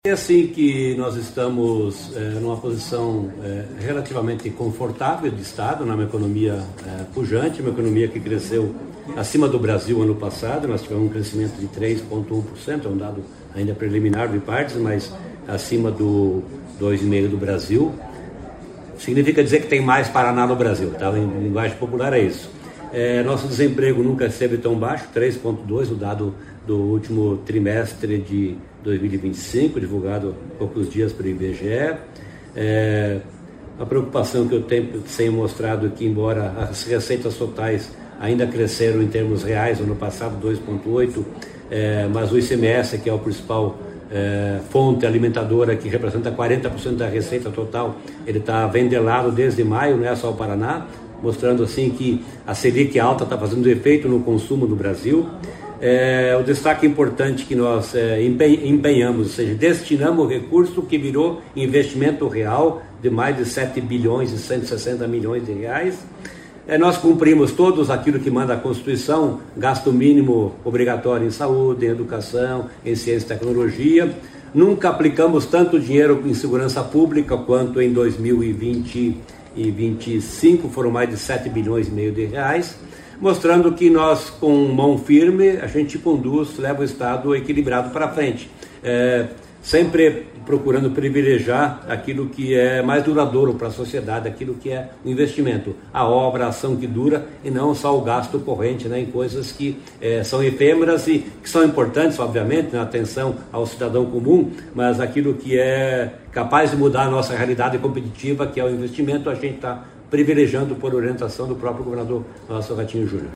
Sonora do secretário Estadual da Fazenda, Norberto Ortigara, sobre o Paraná ter alcançado 93% de execução orçamentária em 2025 | Governo do Estado do Paraná